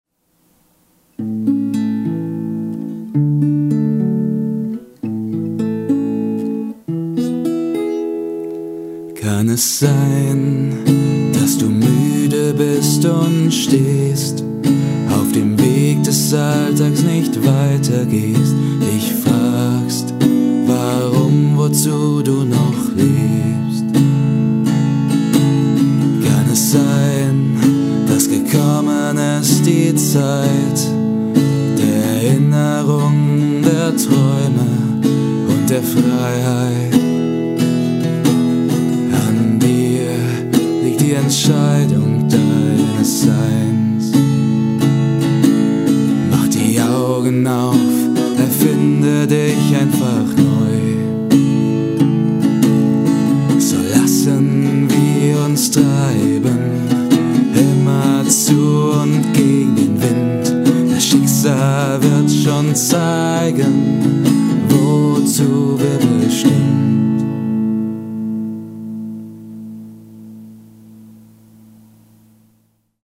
Kategorie: Gesungenes